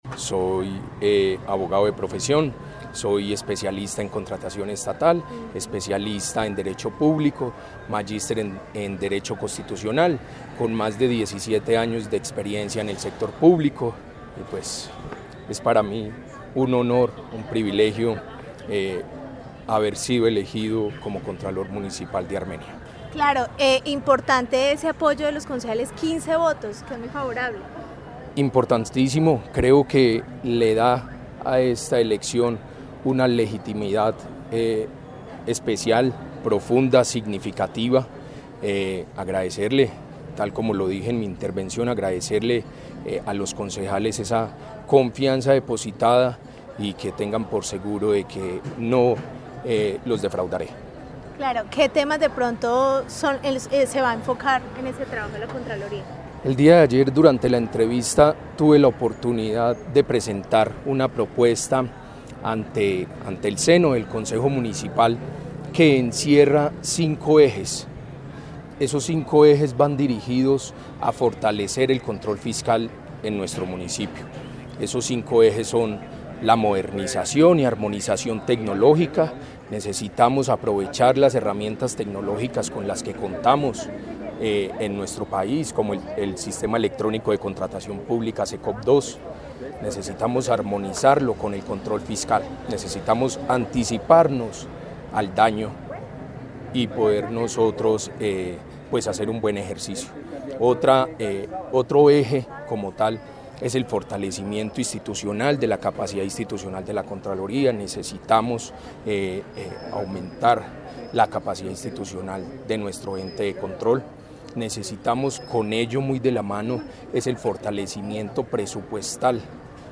En diálogo con Caracol Radio, el nuevo contralor evidenció su optimismo por la designación puesto que fue un respaldo importante al obtener 15 votos de 19 de los corporados por eso agradeció el apoyo.